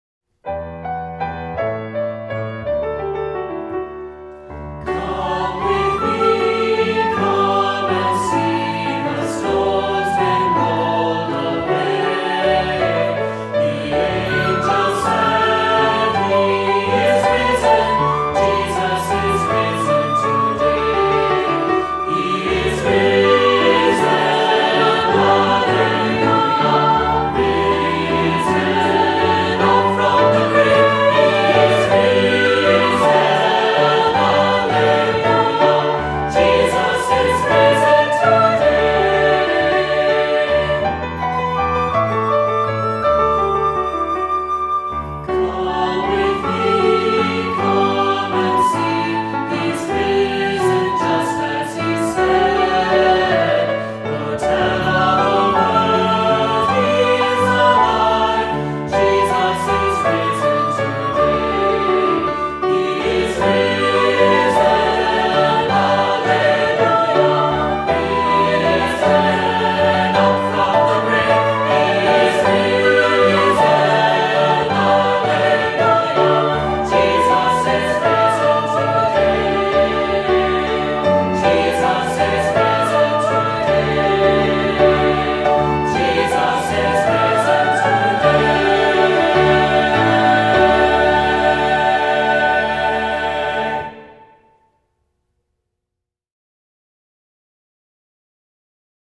Voicing: Two-part choir; optional descants; Assembly